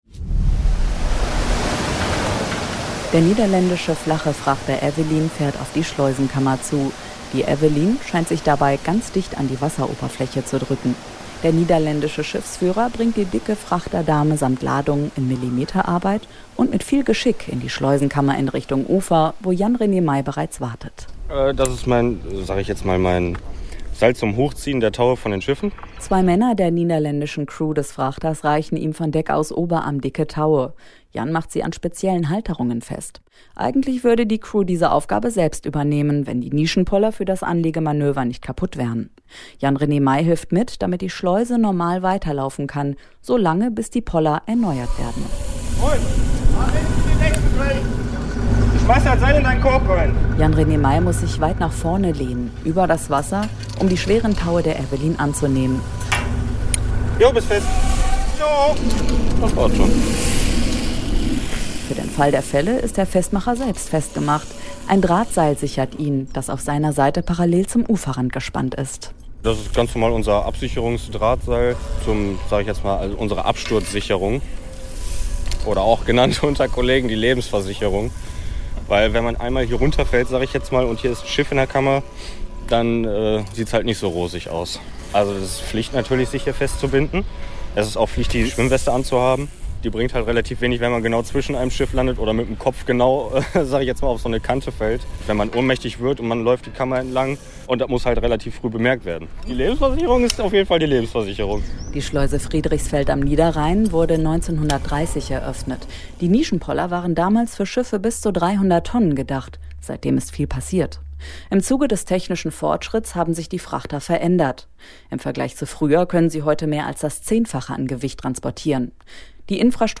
Radio-Feature (Auszug)